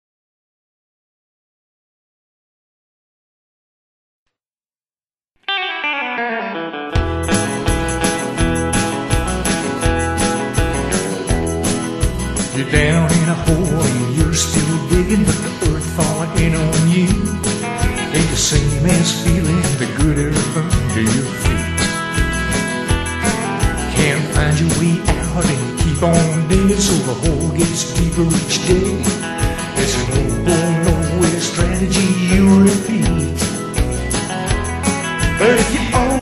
in a Country style